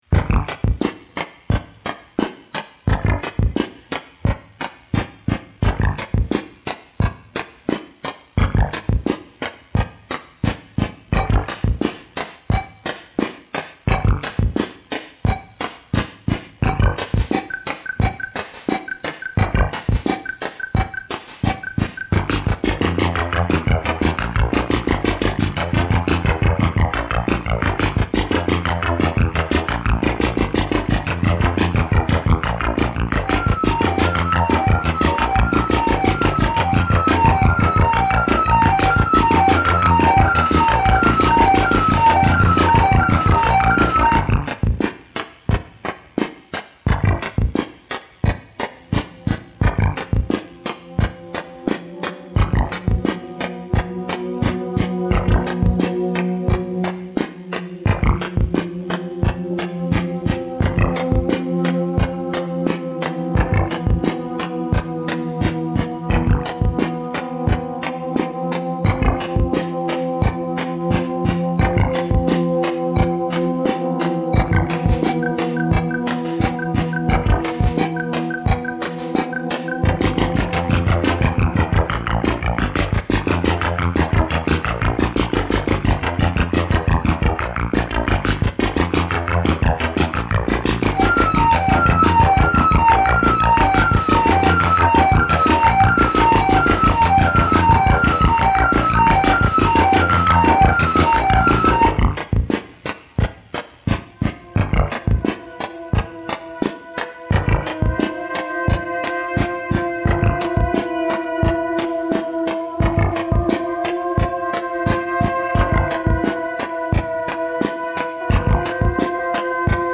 I'd try to match the DR's tempo with the VL-Tone's built-in rhythm box.
Sneaked a little trumpet Frippertronics on varispeed tape in there, too.